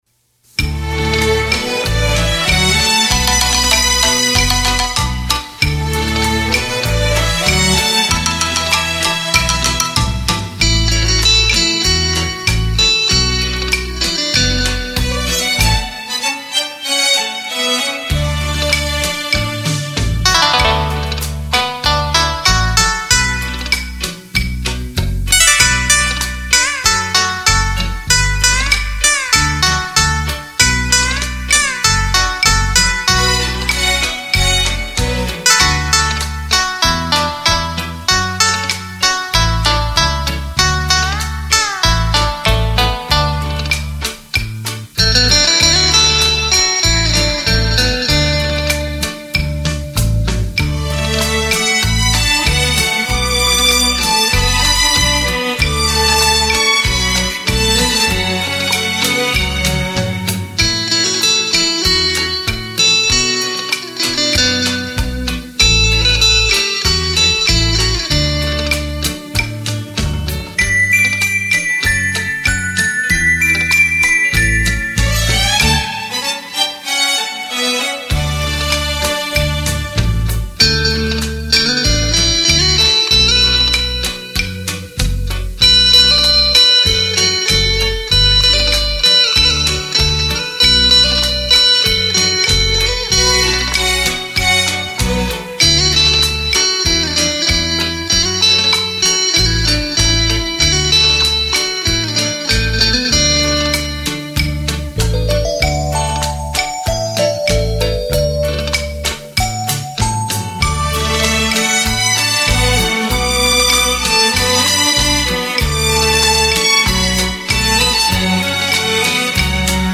专辑格式：DTS-CD-5.1声道
倫巴